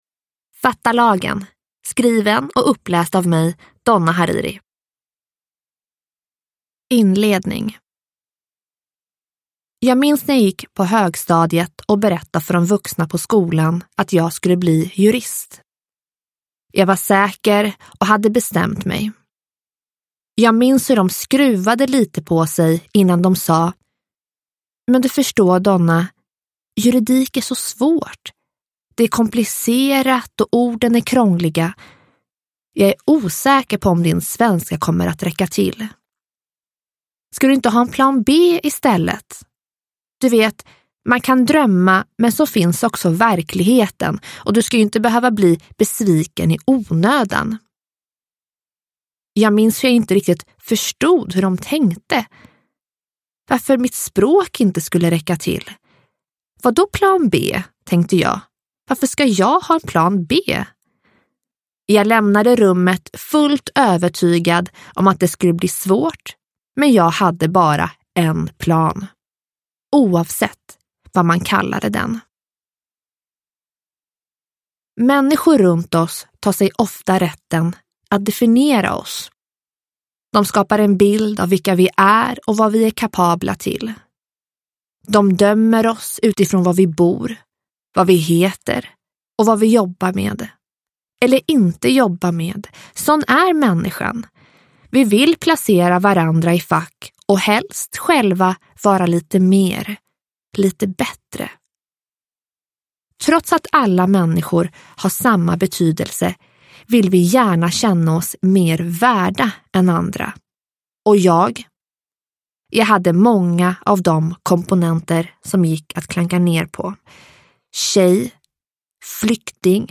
Fatta lagen! : från hatbrott till snatteri och svartjobb – Ljudbok – Laddas ner